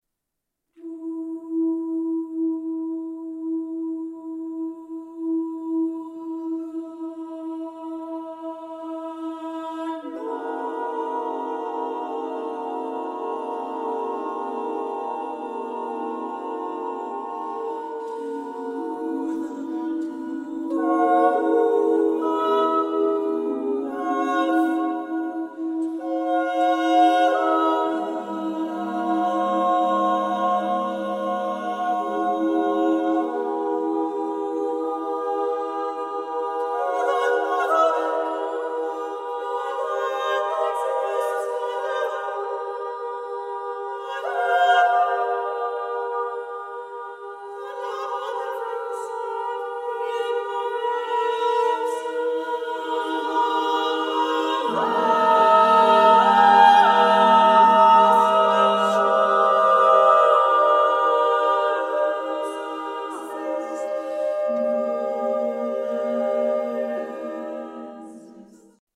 treble voice choir
Recorded August 8, 2021, Mechanics Hall, Worcester, MA